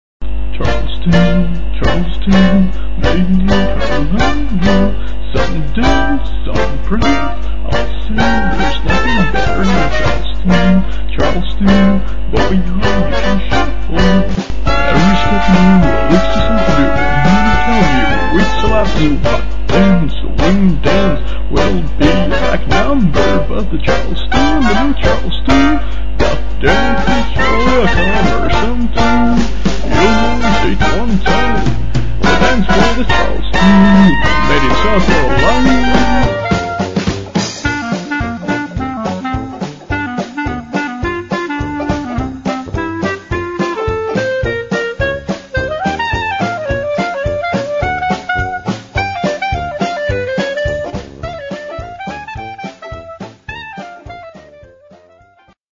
I love singing even though I stink at it.